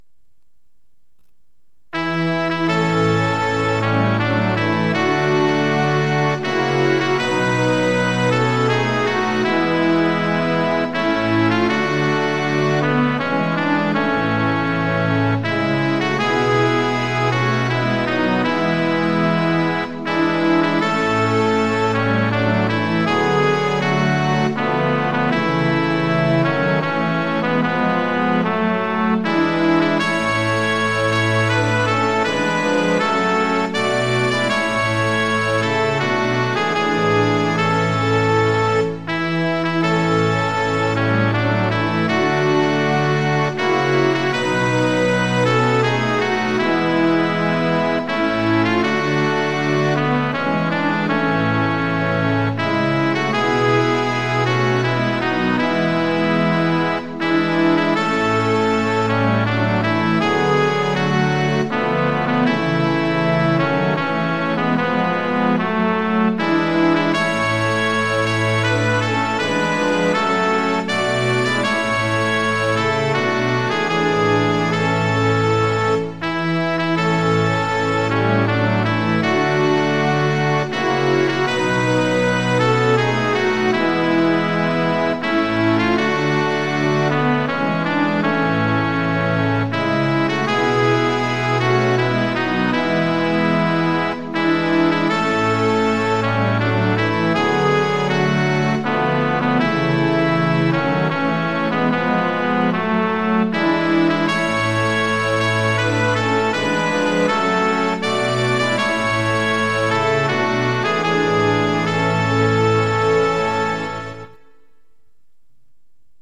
◆　４分の３拍子　：　３拍目から始まります。
●　「主イエズス」は、「主・イエ・ズス」の３音で歌います。